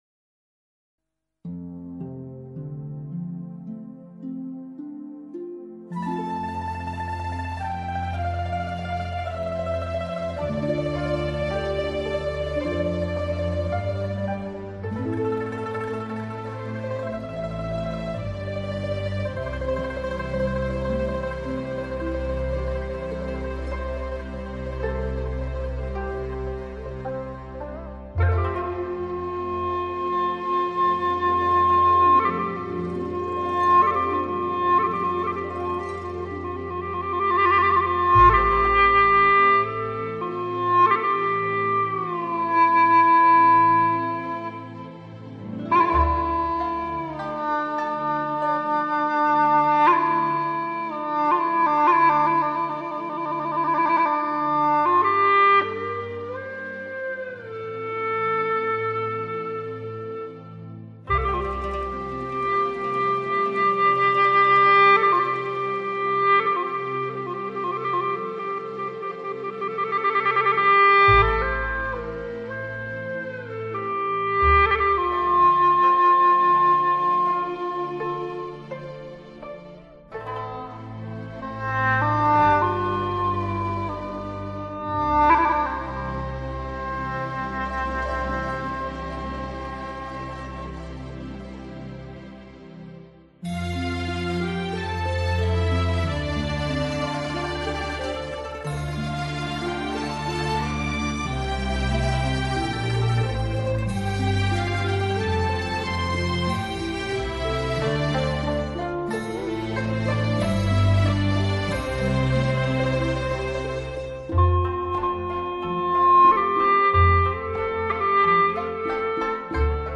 调式 : F 曲类 : 独奏